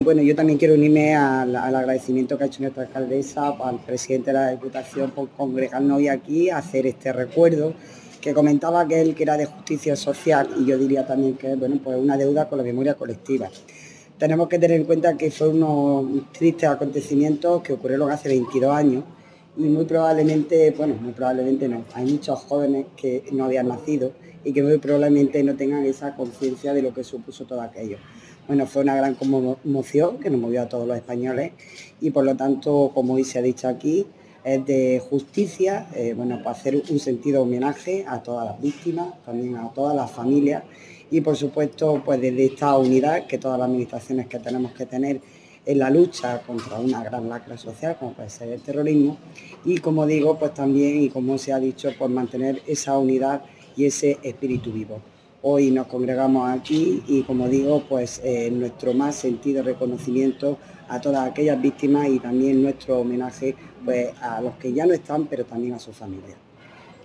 La Diputación, el Ayuntamiento de Almería y la Junta de Andalucía se han unido en un emotivo y solemne minuto de silencio que se ha guardado en la puerta de la institución provincial esta mañana, en la calle Navarro Rodrigo, en homenaje y recuerdo de todas las víctimas de terrorismo.